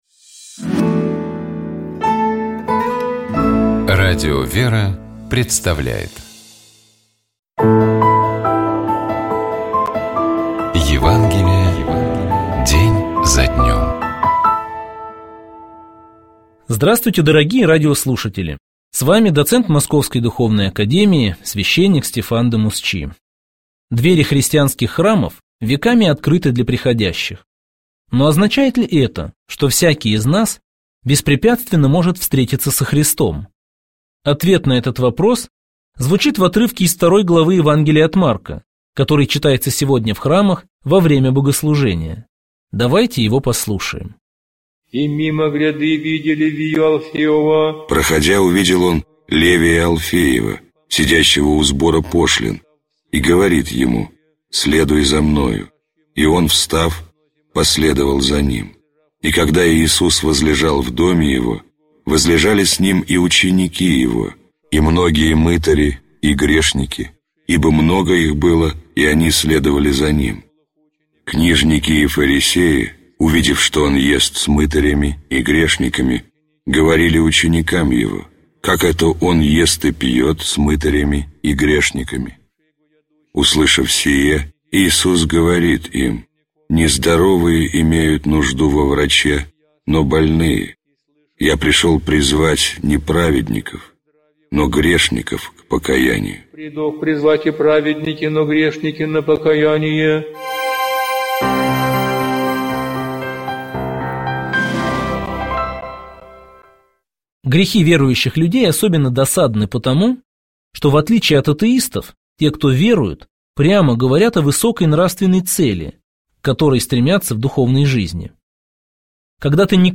Читает и комментирует